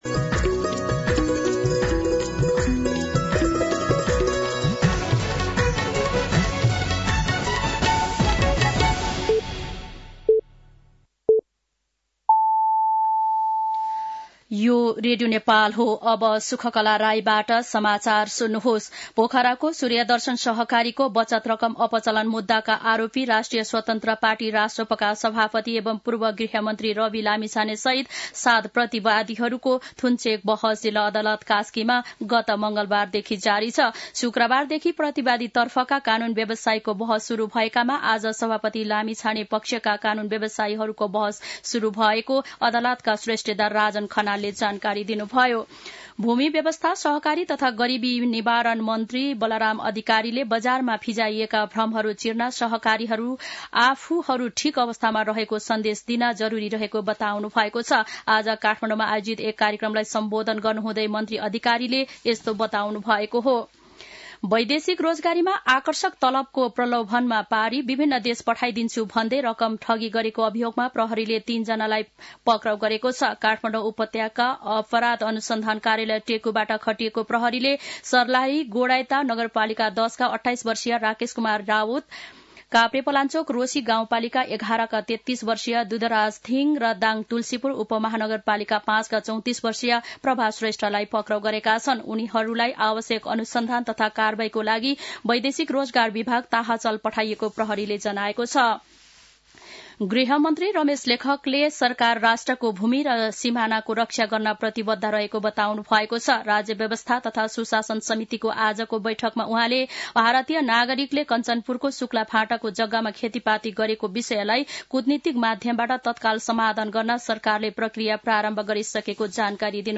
5-pm-news-1.mp3